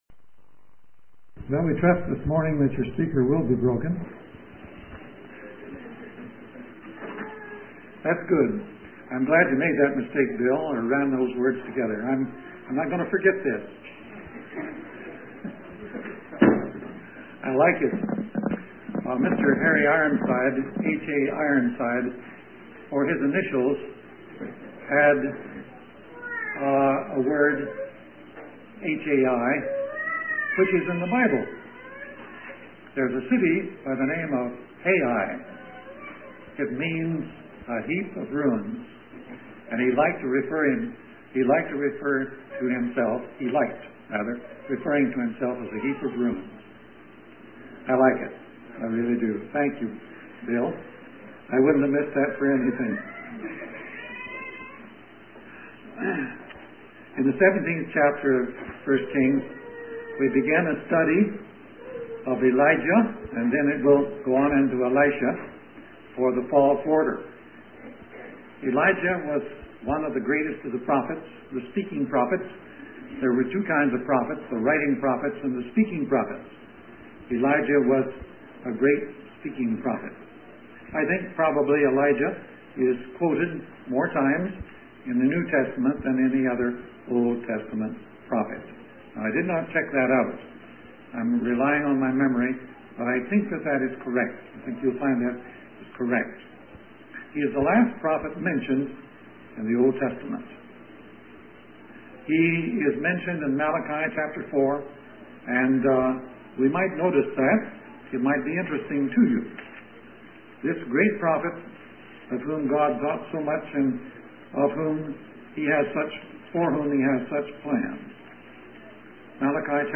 In this sermon on Deuteronomy chapter 11, the preacher emphasizes the importance of diligently obeying God's commandments and loving Him with all our hearts and souls. He highlights the promise of God to provide rain in its due season, along with abundant crops and provisions for His people.